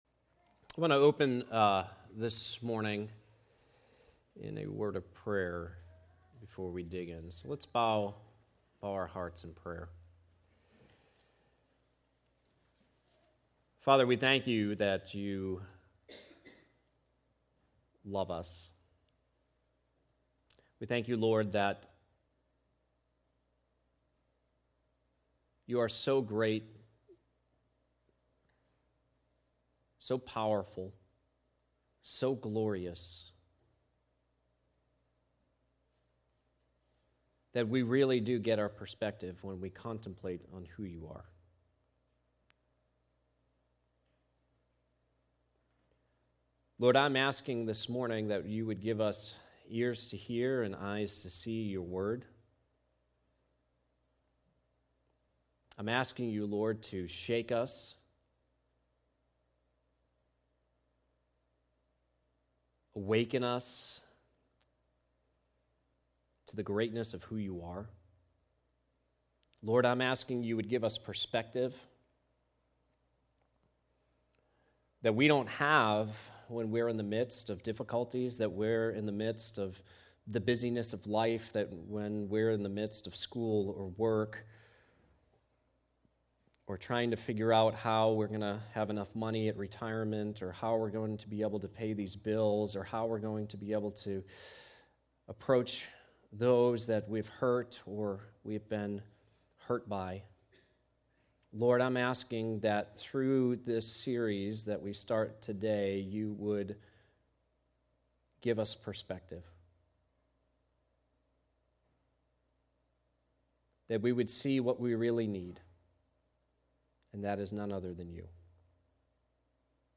Sermons | Gospel Life Church